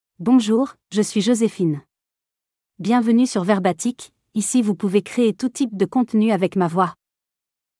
FemaleFrench (France)
JosephineFemale French AI voice
Josephine is a female AI voice for French (France).
Voice sample
Listen to Josephine's female French voice.
Josephine delivers clear pronunciation with authentic France French intonation, making your content sound professionally produced.